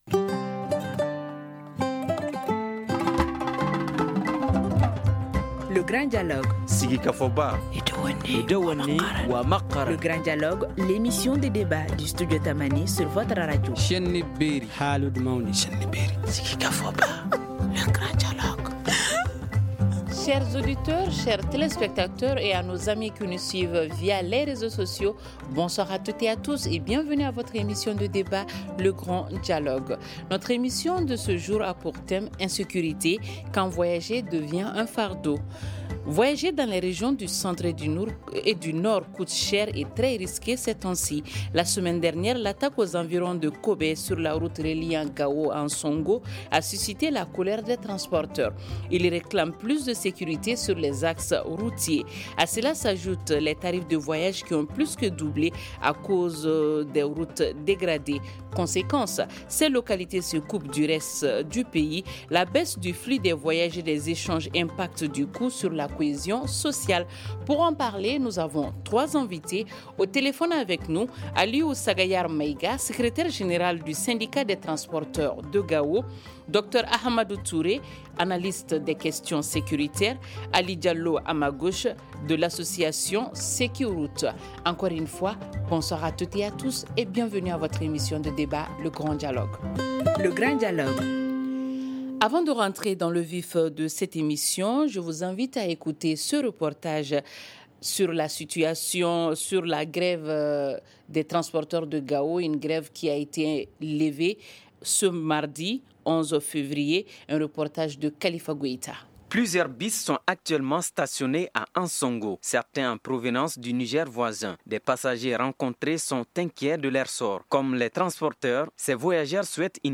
La baisse du flux des voyages et des échanges impacte du coup sur la cohésion sociale. Pour en parler, nos invités sont :